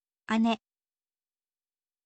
ane